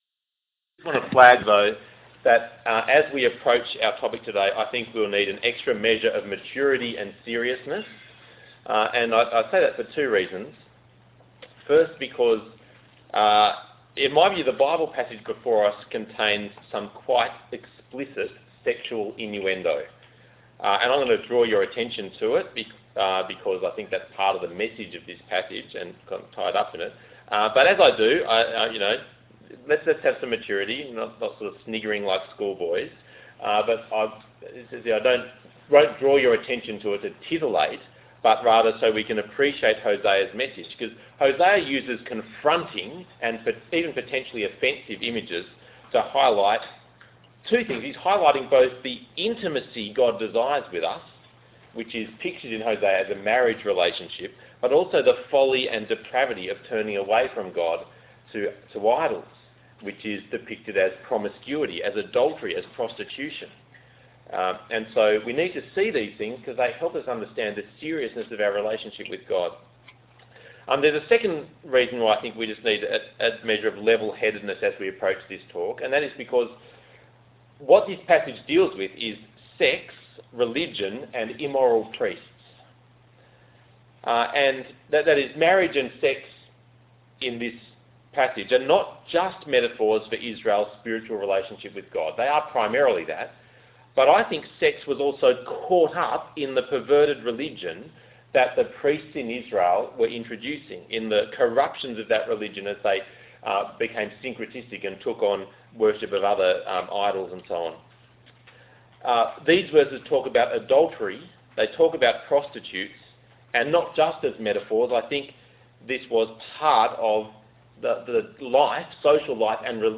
Hosea Passage: Hosea 4:1-5:7 Talk Type: Bible Talk « Hosea Talk 2